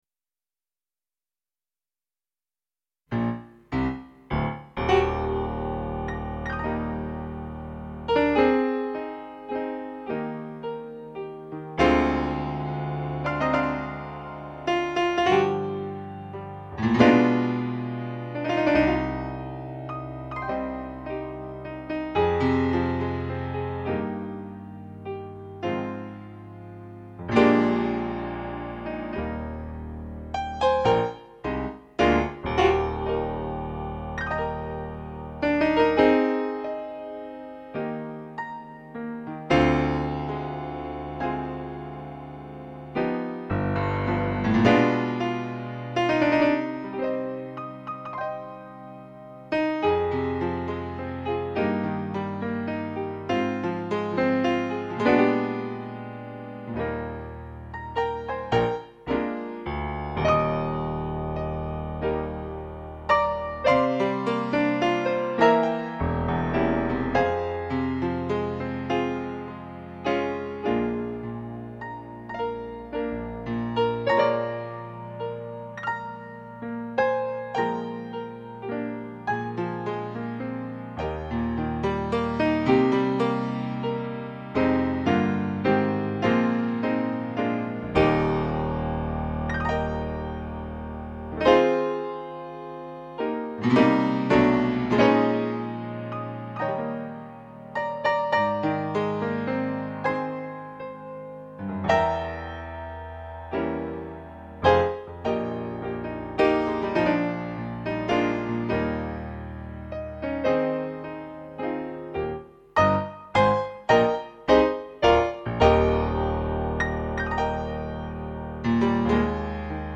Playbacks-KARAOKE